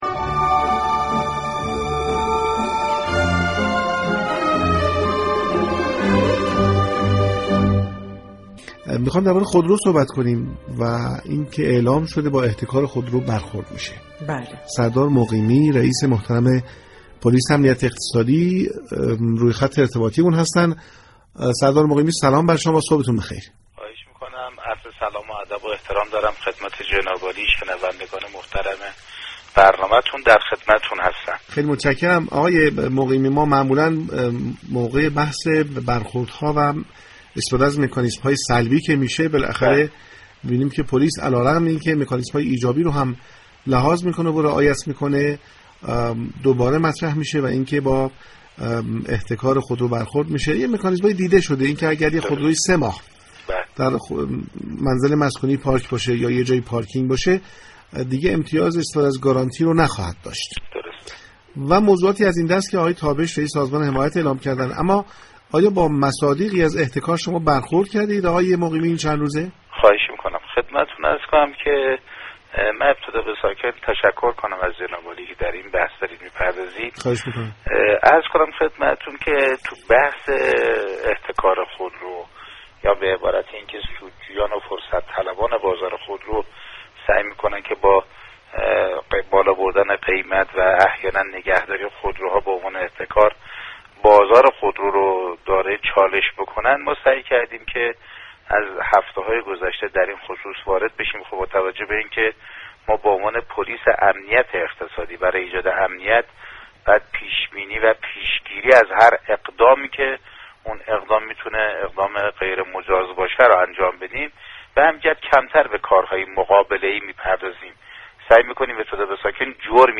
سردار محمدرضا مقیمی، رئیس پلیس امنیت اقتصادی درباره علت گرانی خودرو با پارك شهر شنبه 27 اردیبهشت گفتگو كرد.